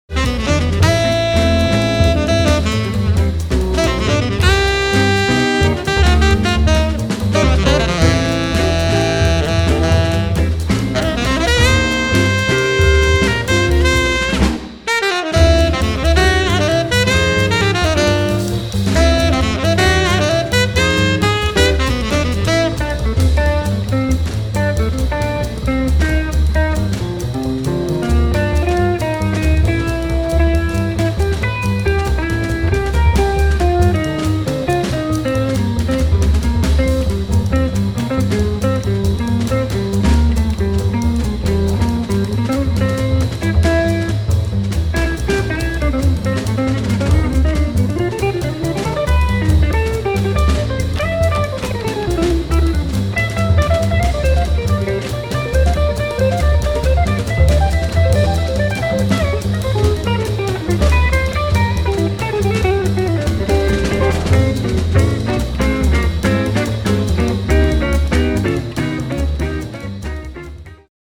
tenor saxophone
drums